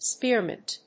spéar・mìnt